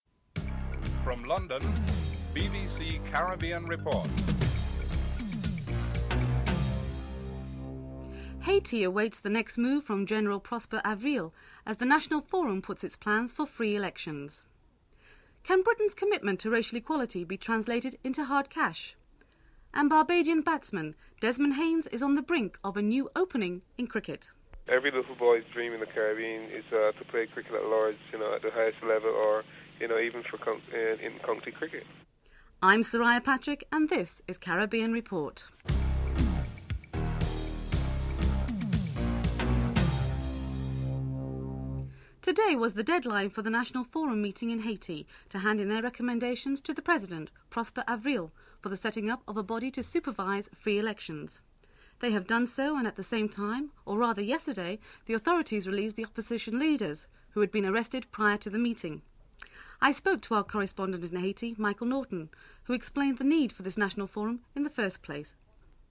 1. Headlines (00:00-00:40)
4. Financial News (05:21-06:18)
5. Interview with Desmond Haynes, West Indies opening batsman on the signing of a contract to play for Middlesex (06:19-08:57)